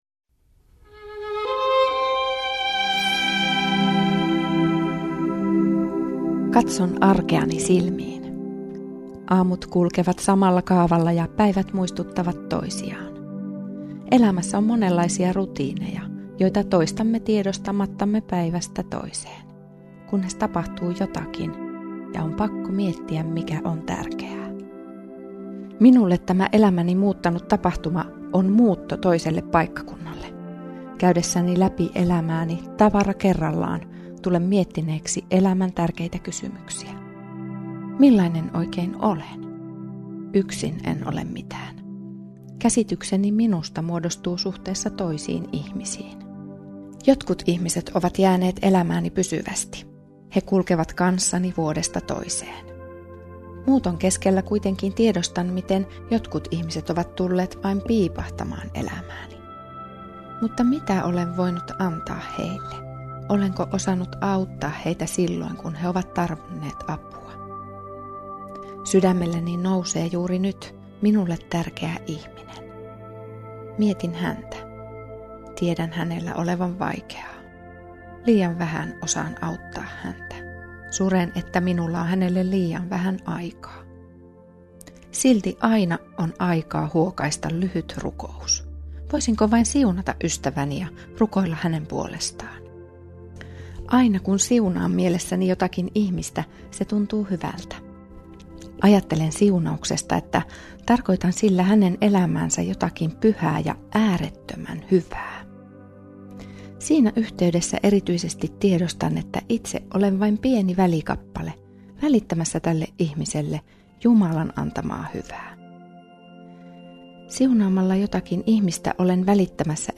Radio Dei lähettää FM-taajuuksillaan radiohartauden joka arkiaamu kello 7.50. Hartaus kuullaan uusintana iltapäivällä kello 17.05.